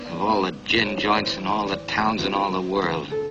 In contrast to the dark bunched r of GenAm, the famous old New York NURSE-vowel with a palatal offglide (stereotyped in spellings like “doity boid” for dirty bird) surely developed from a bunched rhotic vowel that was near the palatal end of my continuum, ie with a high meeting-point for F2 and F3. Older Hollywood movies often feature speakers with this kind of vowel: